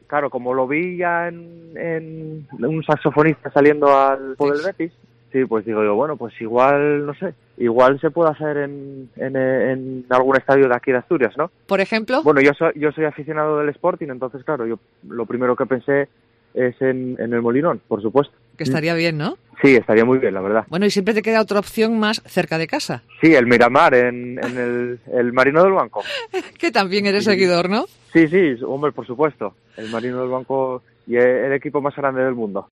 VÍDEO VIRAL EN REDES